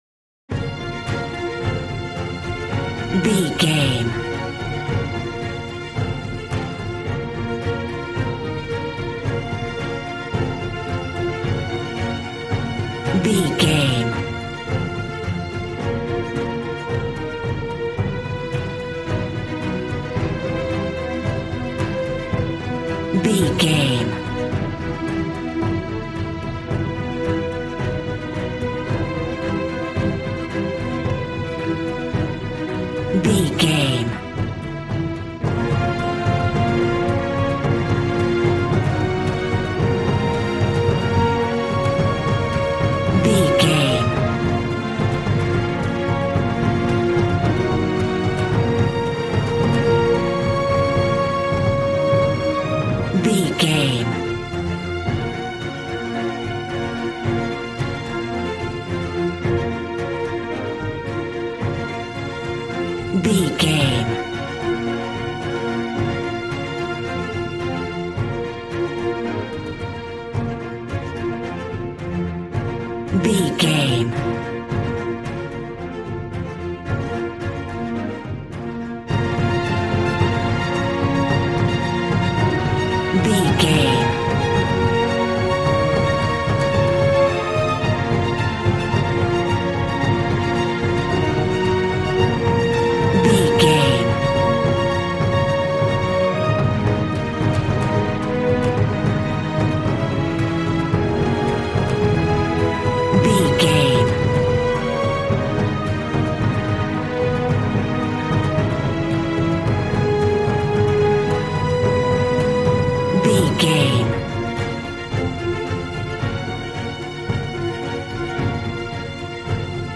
A dark and scary piece of tense classical music.
Aeolian/Minor
B♭
suspense
piano
synthesiser